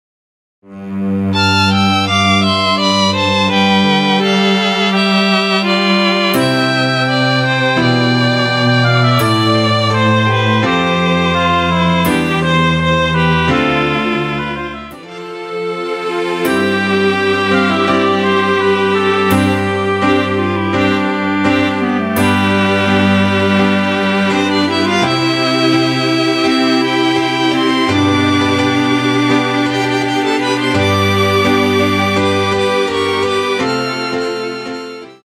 키 C 가수
원곡의 보컬 목소리를 MR에 약하게 넣어서 제작한 MR이며
노래 부르 시는 분의 목소리가 크게 들리며 원곡의 목소리는 코러스 처럼 약하게 들리게 됩니다.